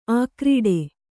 ♪ ākrīḍe